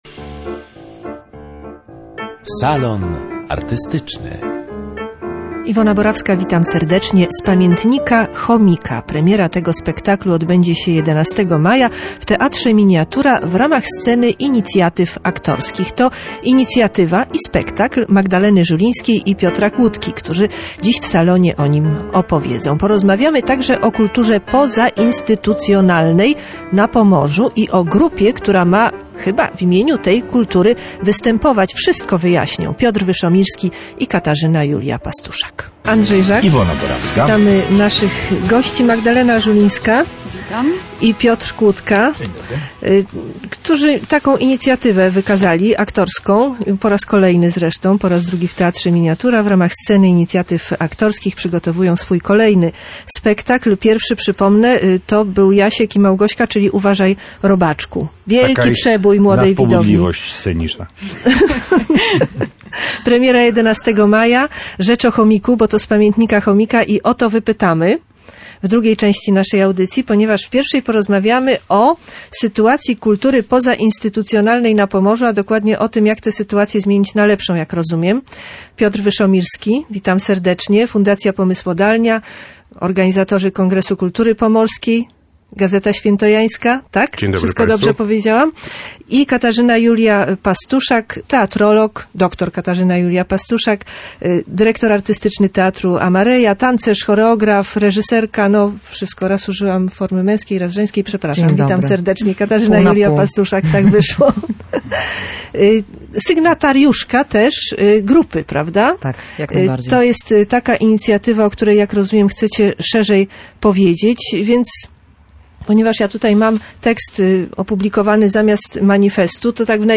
„Z pamiętnika Chomika”. Rozmawiamy z twórcami